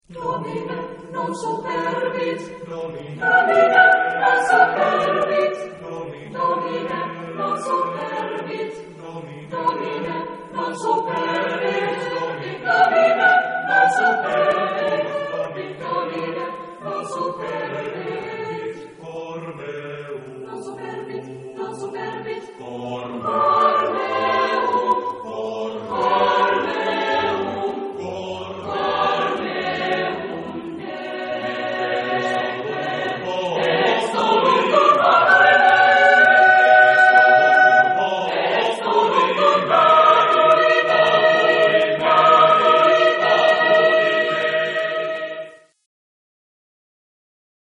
Género/Estilo/Forma: Sagrado ; Plegaria ; Salmo
Tipo de formación coral: SATB  (4 voces Coro mixto )
Tonalidad : diversas